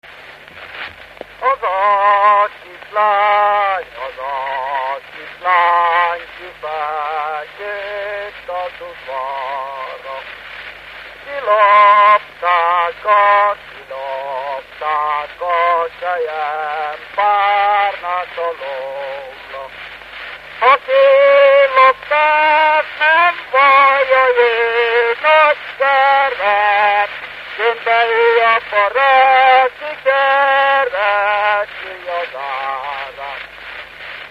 Alföld - Szatmár vm. - Nyírmeggyes
ének
Stílus: 8. Újszerű kisambitusú dallamok
Kadencia: 1 (1) 5 1